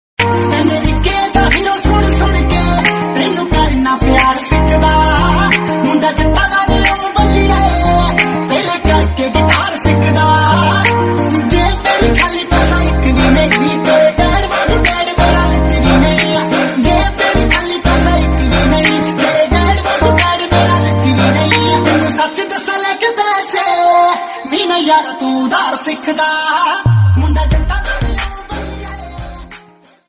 India